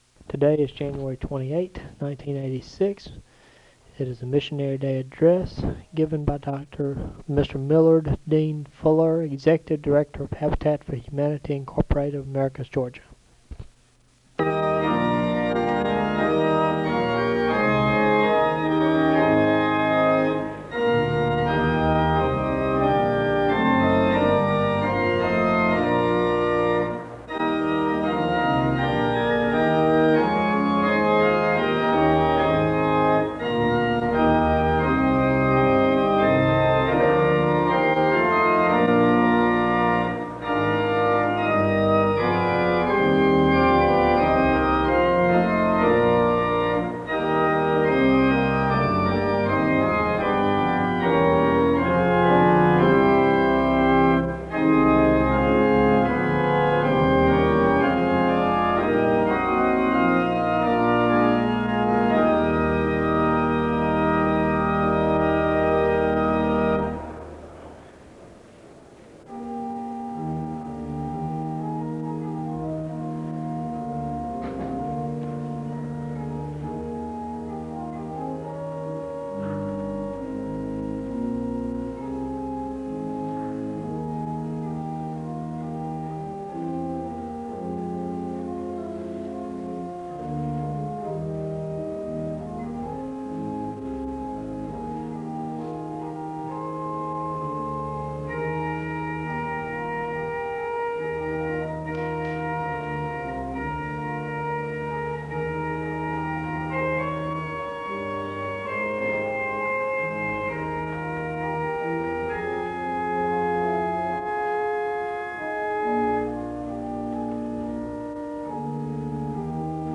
File Set | SEBTS_Chapel_Millard_Fuller_1986-01-28.wav | ID: 4858d58f-99b7-47e0-95fe-b47c875edc91 | Hyrax